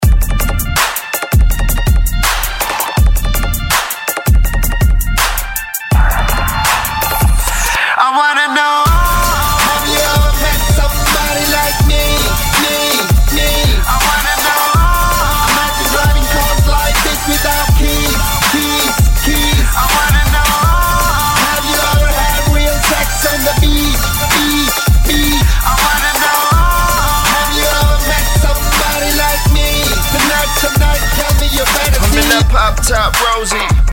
• Качество: 192, Stereo
Отличный реп-звонок и будильник!